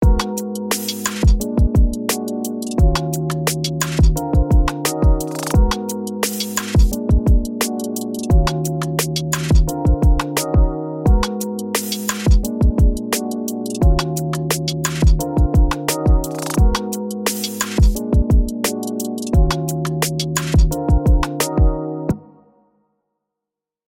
サンプルとして、「Neo Soul Minor」というプリセットを聴いてみましょう。
このように、よく耳にするコード和音が備わっているので、手軽かつ実用的に使用できます。